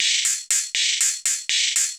Index of /musicradar/ultimate-hihat-samples/120bpm
UHH_ElectroHatA_120-05.wav